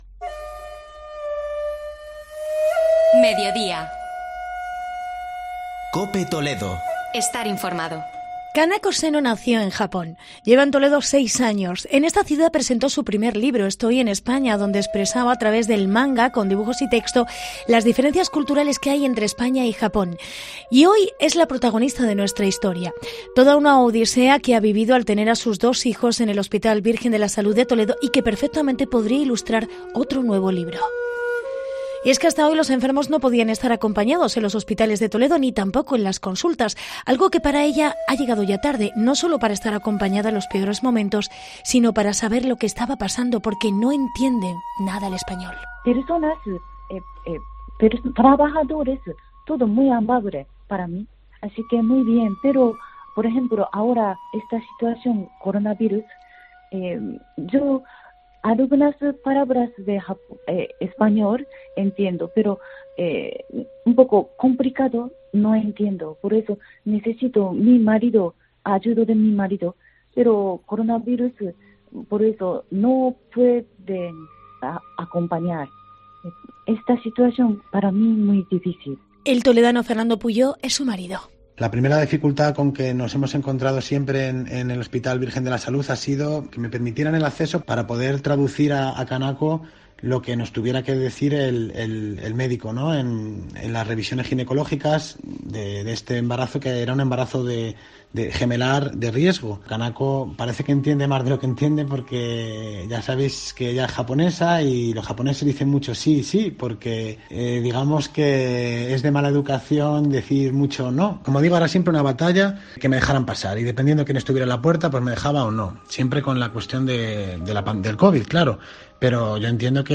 AUDIO: Reportaje